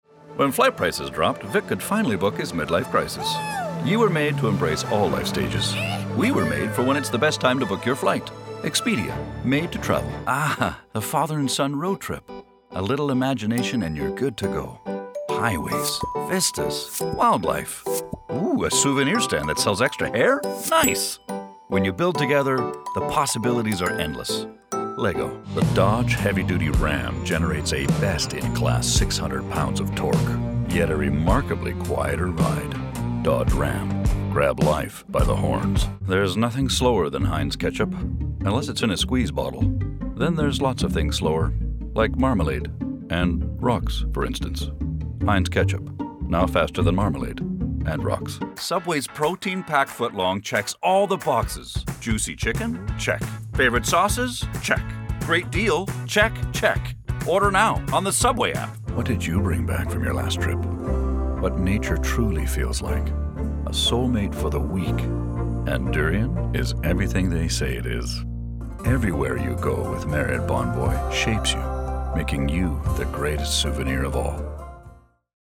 Voice Over Artist
• Smooth
• Passionate
• Warm
• Bold
Commercial Demo
• AT 2020 microphone